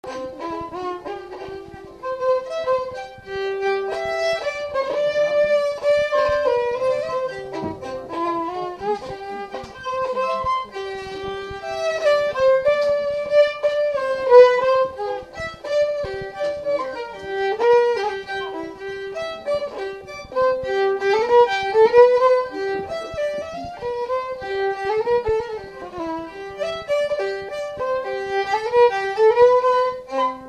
Séga
Saint-Louis
Instrumental
danse : séga
Pièce musicale inédite